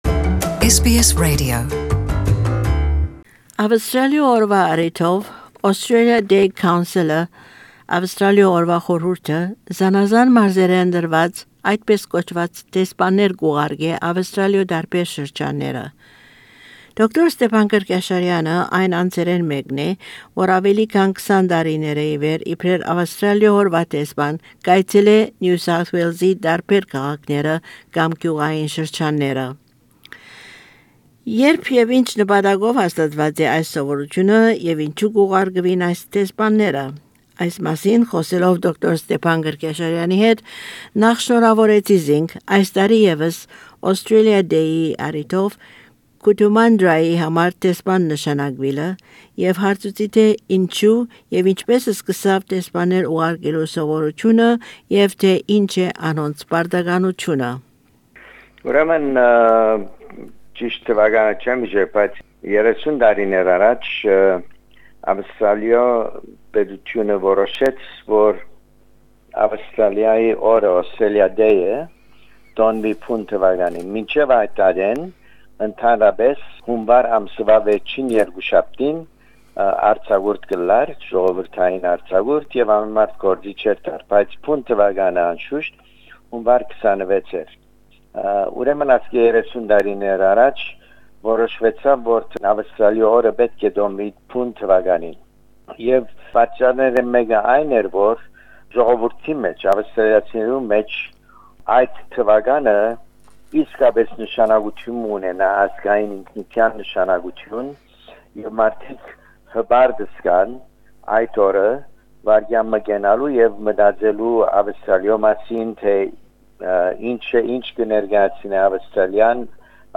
Interview with Dr Stepan Kerkyasharian OA, community leader, as Australia Day ambassador to Cootamandra.